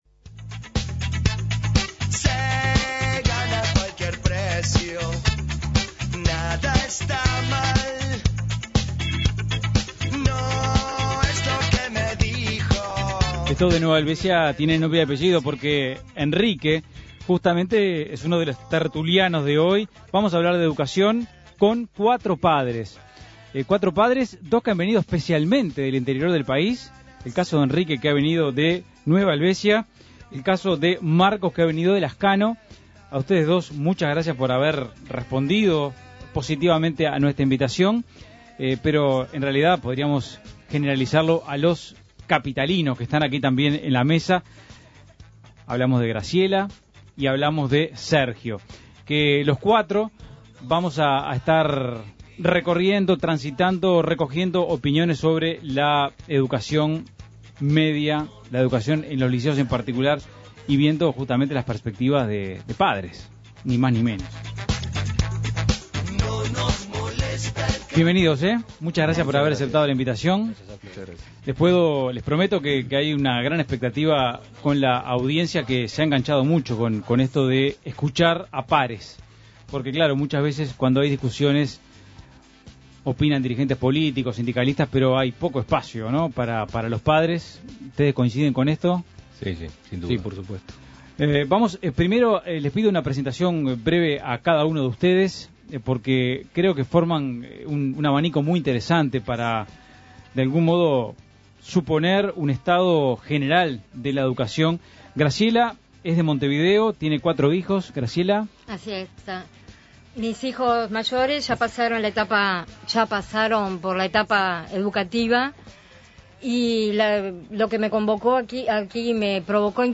Tertulia especial de padres.